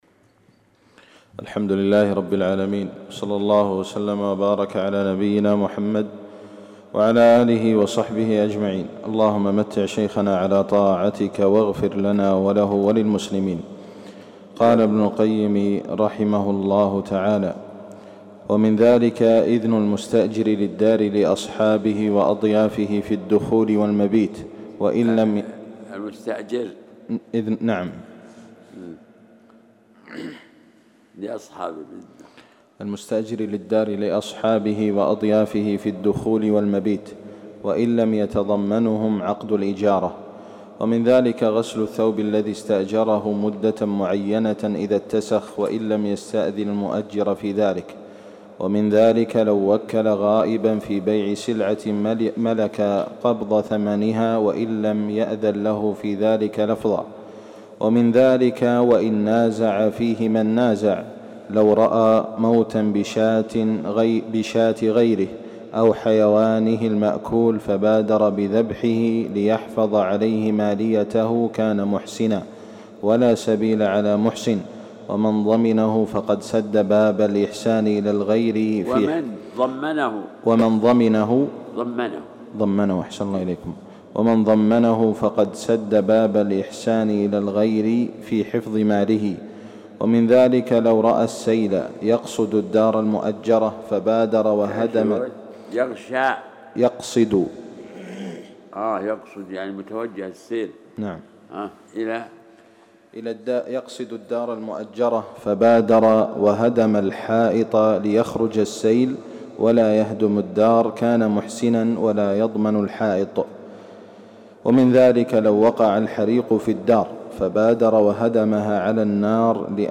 بسمِ اللهِ الرّحمنِ الرَّحيم التَّعليق على كتاب (الطّرق الحُكميَّة في السّياسة الشَّرعيَّة) لابن قيّم الجوزيَّة الدّرس السّادس *** *** *** ***